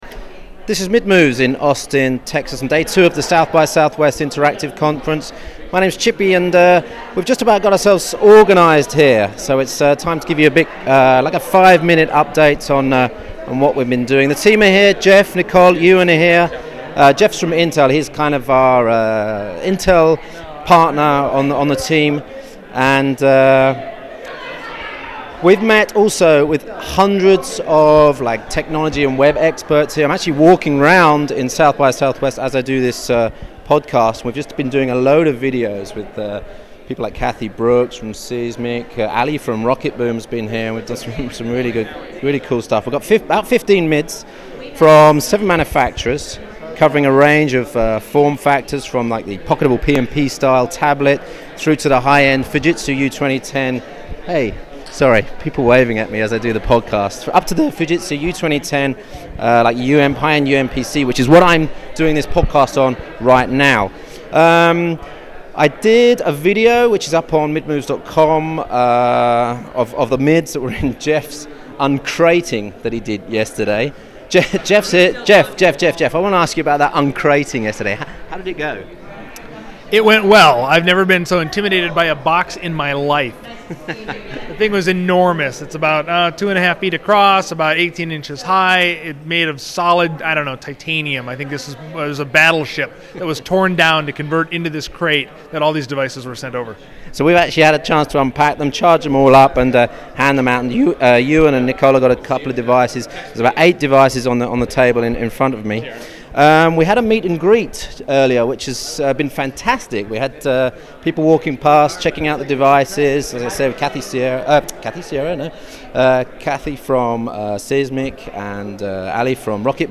Listen to a mobile podcast, recorded on the Fujitsu U2010 a short while after a bunch of video interviews.